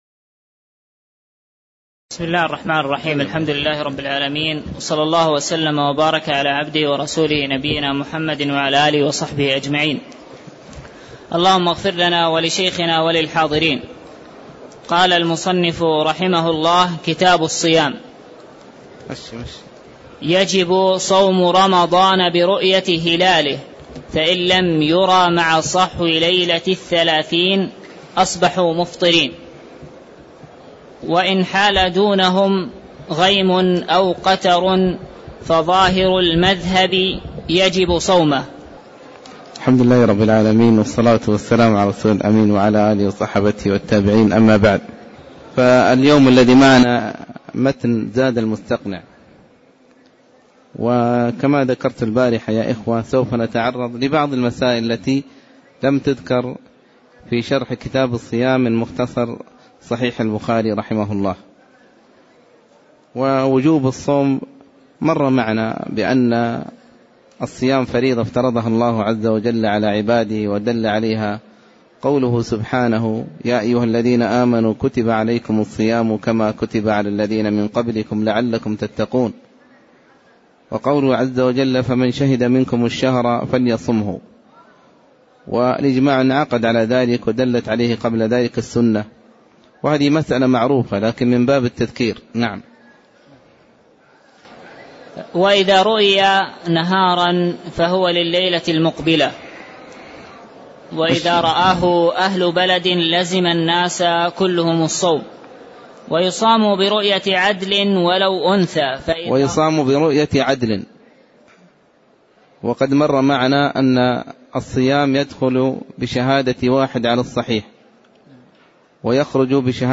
تاريخ النشر ٢٣ شعبان ١٤٣٧ هـ المكان: المسجد النبوي الشيخ